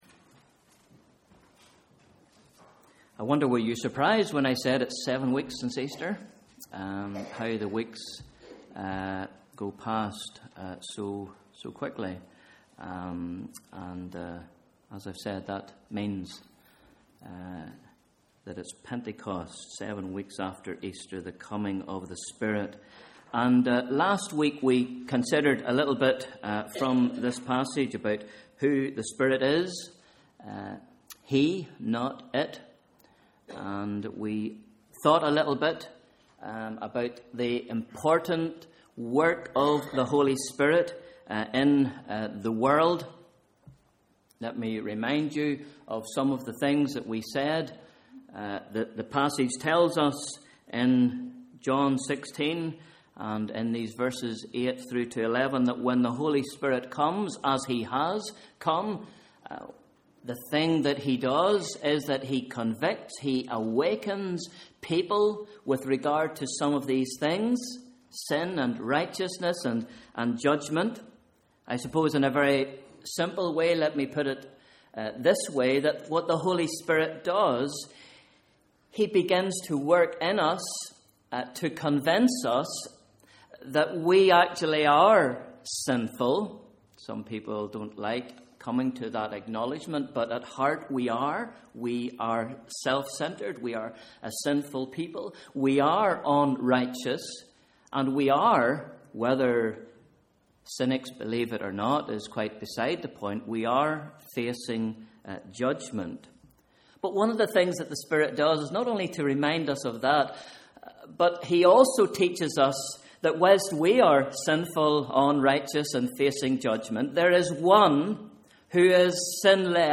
Morning Service: Sunday 19th May 2013